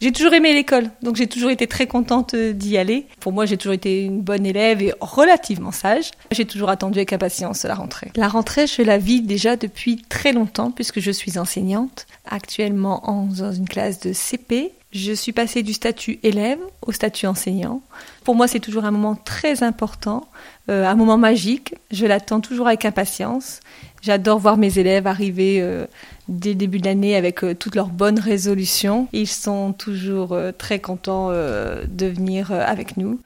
Souvenirs de rentrée : témoignage de Corinne Noël
Radio 6 est allée à la rencontre des maires du Calaisis pour évoquer leurs souvenirs de rentrée lorsqu'ils étaient enfants. Aujourd’hui, c’est au tour de Corinne NOEL, la maire de Marck, de se confier.